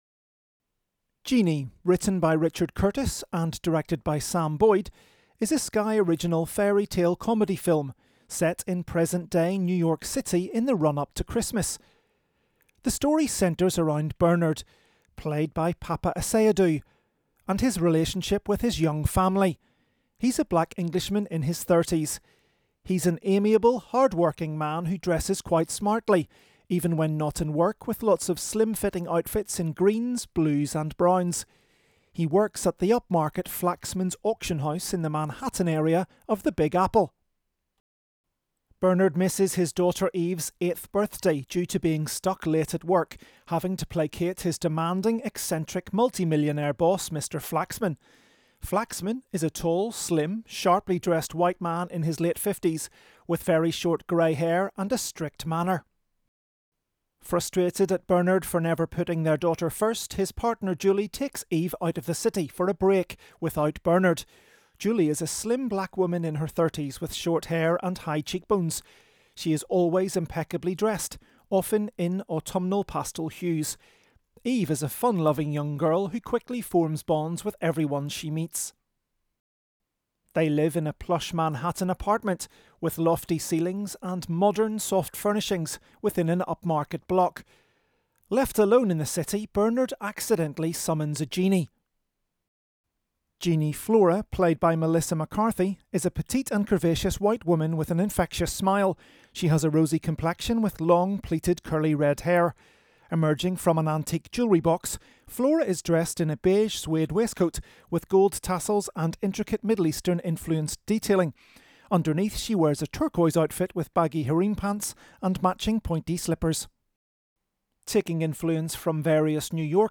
Genie_AD_Intro.wav